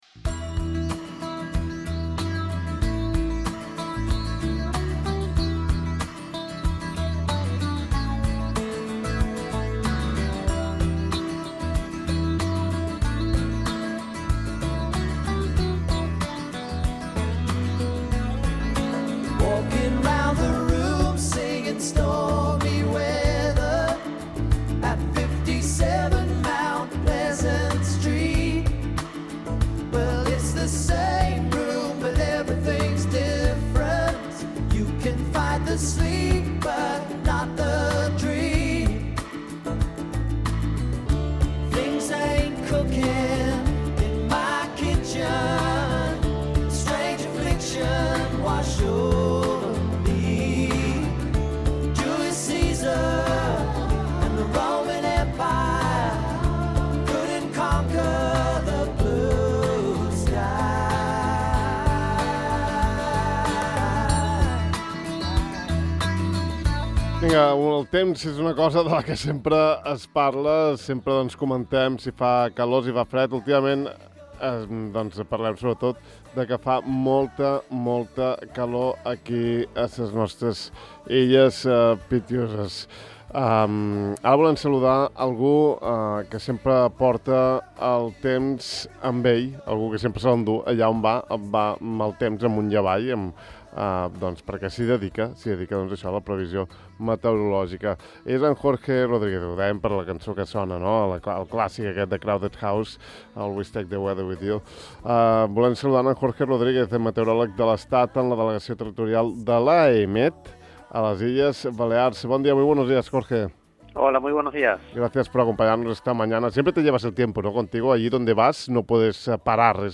hem entrevistat